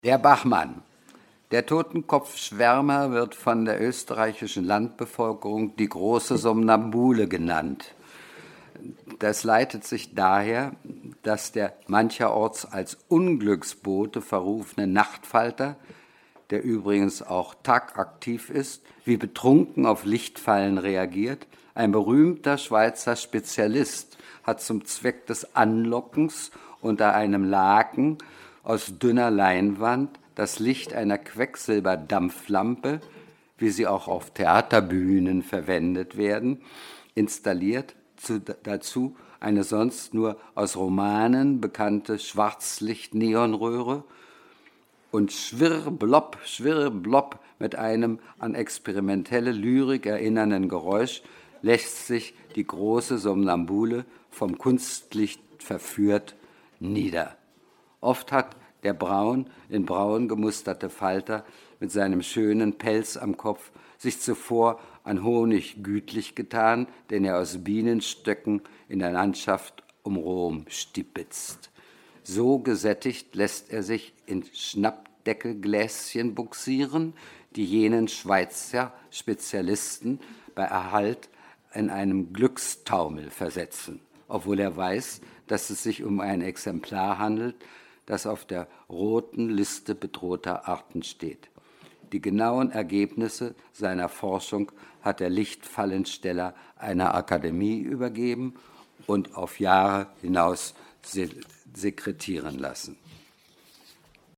Fritz J. Raddatz liest im LCB am 23.8.2012 aus seinem Bestiarium der deutschen Literatur: Der Bachmann